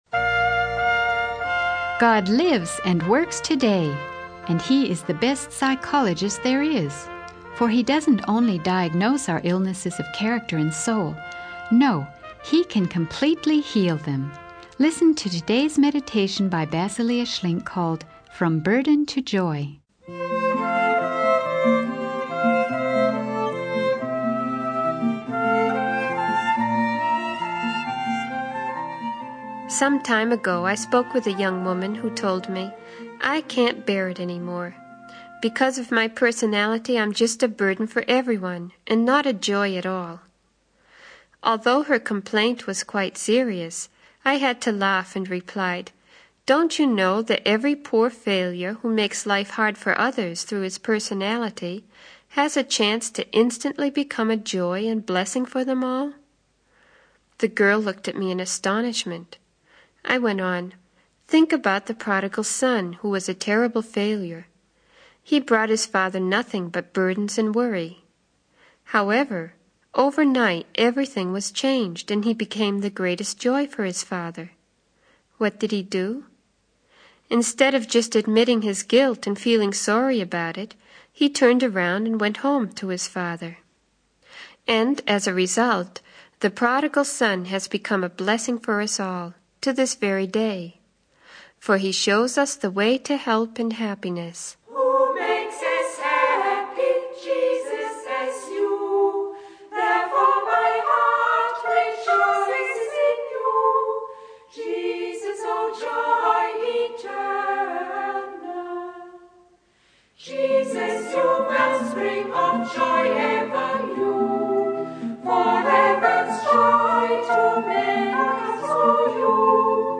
In this sermon, the speaker discusses the story of the prodigal son and how he turned his life around by repenting and returning to his father.